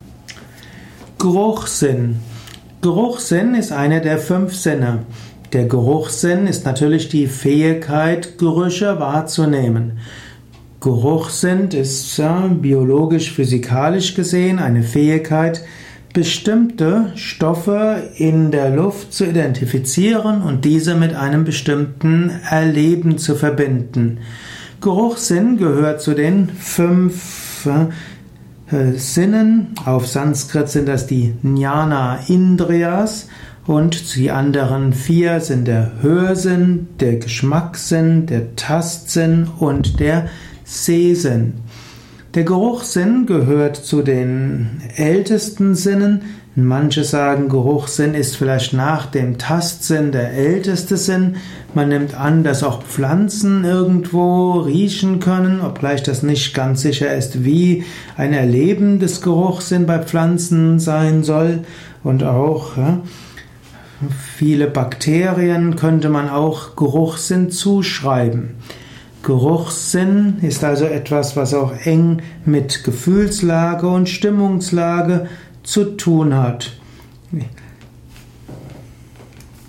Ein Kurzvortrag über den Geruchssinn